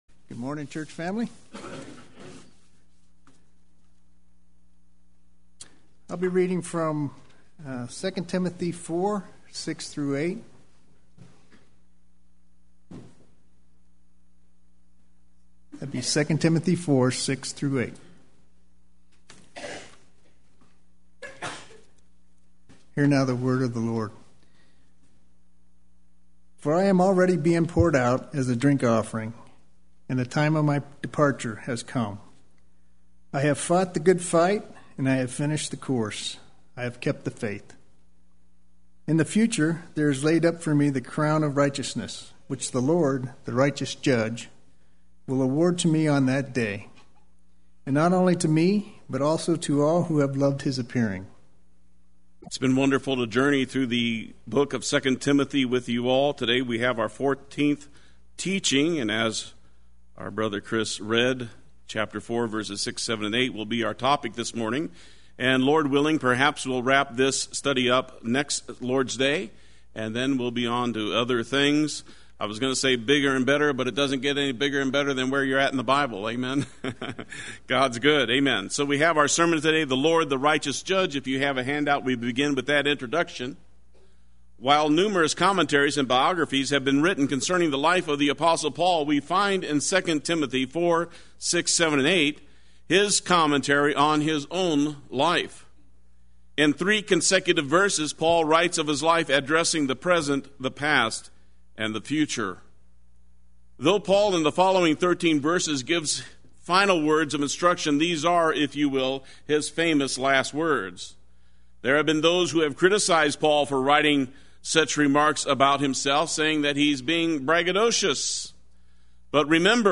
Play Sermon Get HCF Teaching Automatically.
the Righteous Judge Sunday Worship